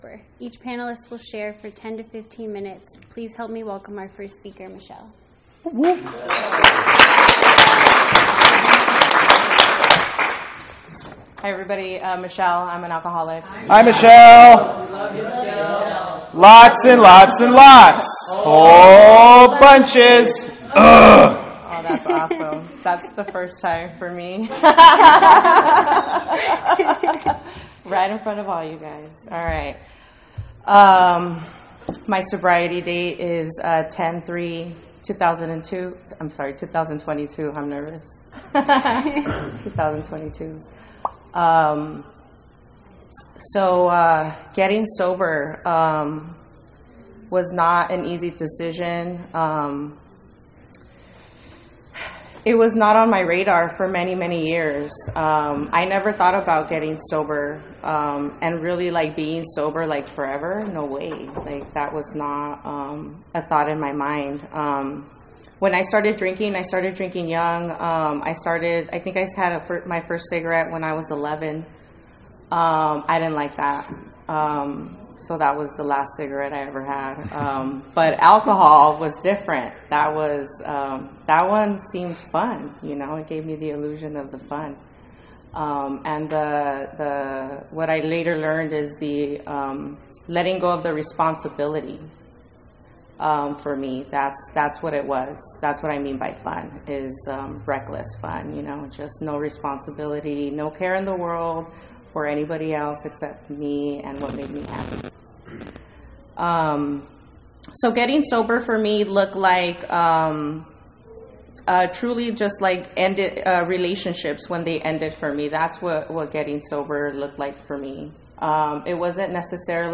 DCYPAA PANEL - AA - 50th MAAD DOG DAZE 2024